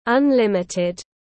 Lớn vô hạn tiếng anh gọi là unlimited, phiên âm tiếng anh đọc là /ʌnˈlɪm.ɪ.tɪd/.
Unlimited /ʌnˈlɪm.ɪ.tɪd/